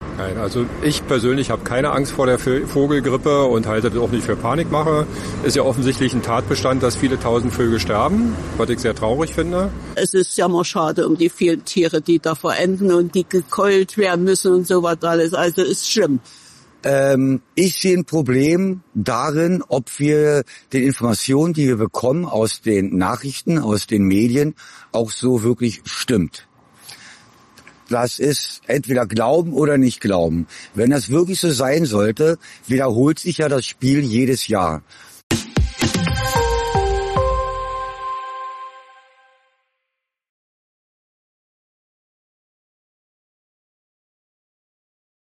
AUF1 hat sich umgehört – in Zepernick, einer
Gemeinde vor den Toren Berlins.